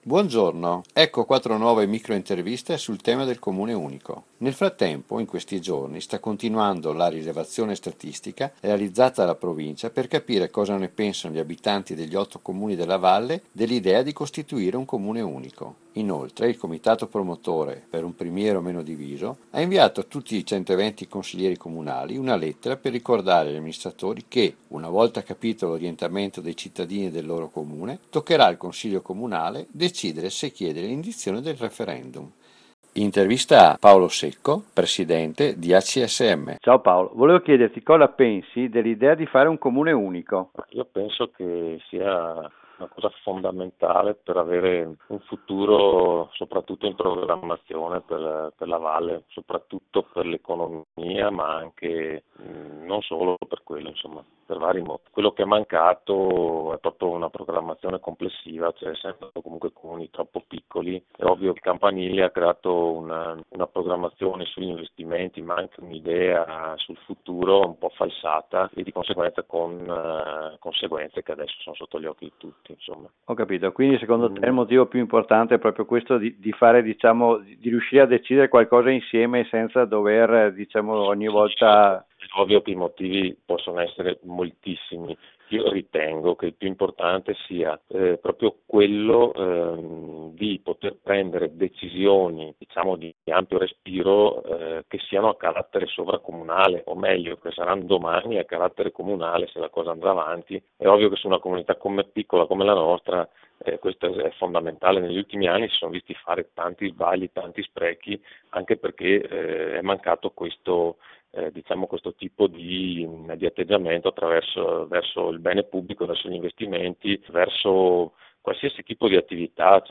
Interviste per Un Primiero Meno Diviso